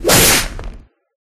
Slash10.ogg